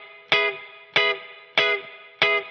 DD_TeleChop_95-Emaj.wav